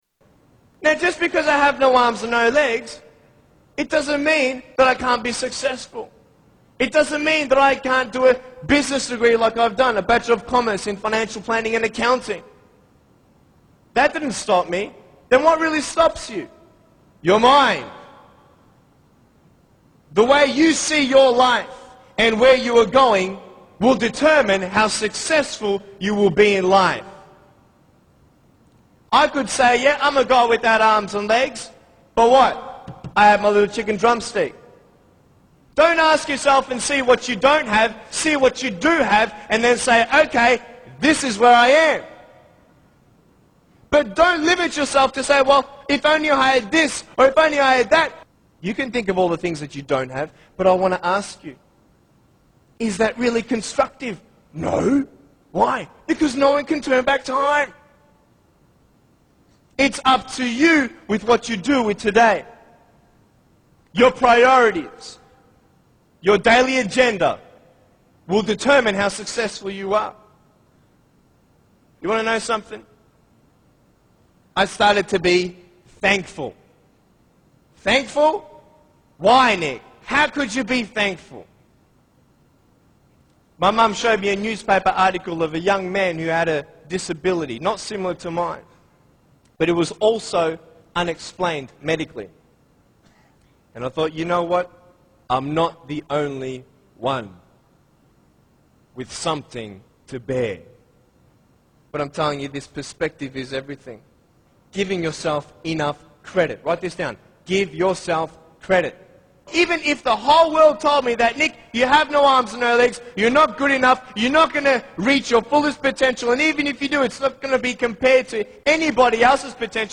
Motivator speaks prt 4